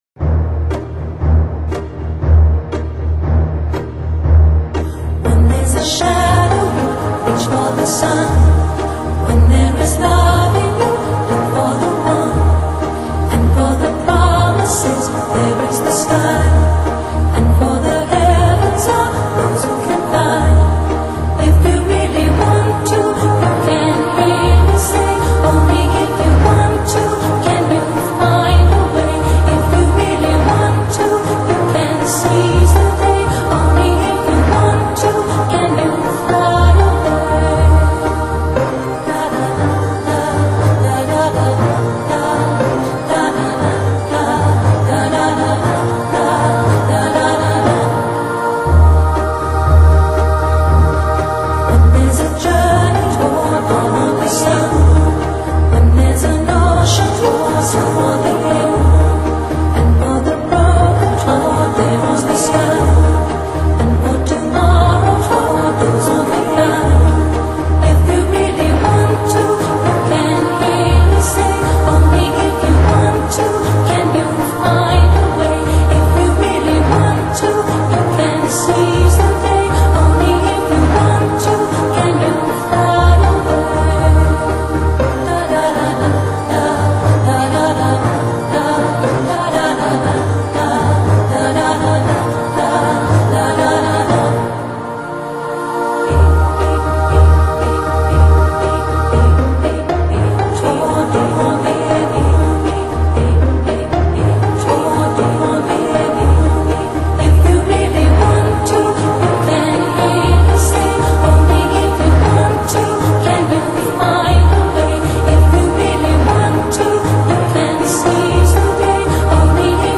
新世纪音乐
时而优 雅脱俗，时而淳朴原始，让人沈淀自己浮动的心。